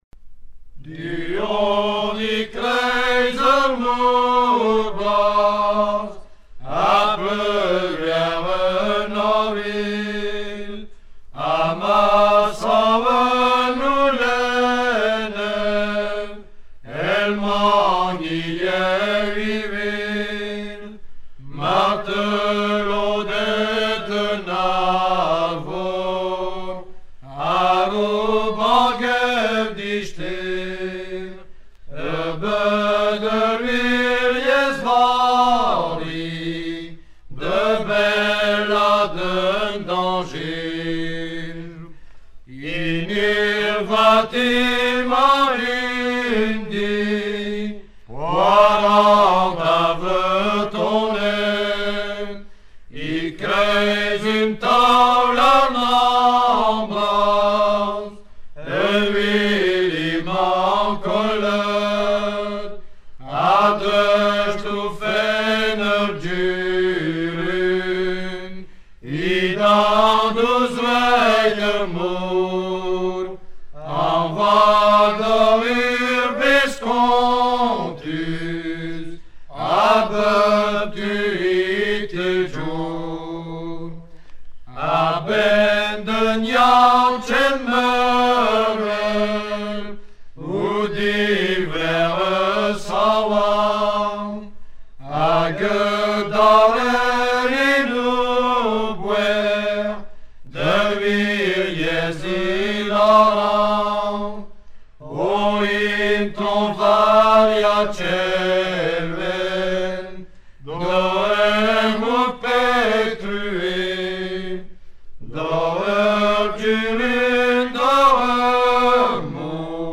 circonstance : cantique
Genre strophique